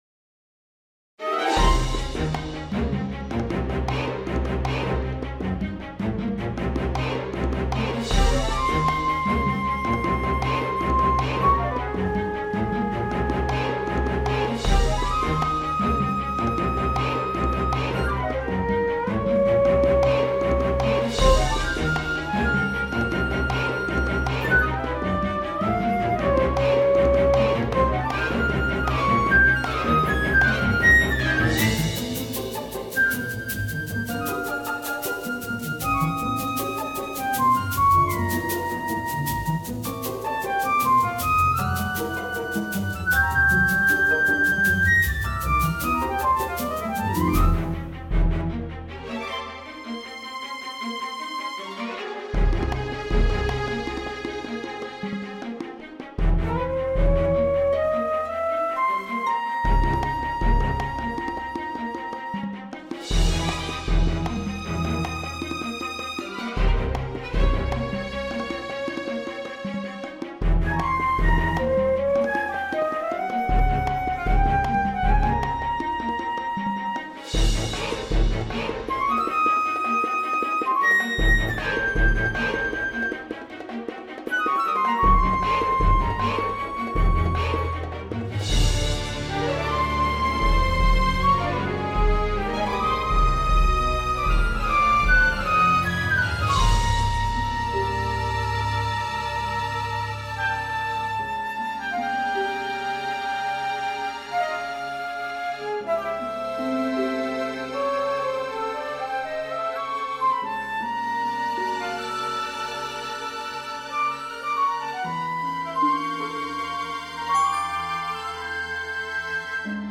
Concert: ORCHESTRAL MUSIC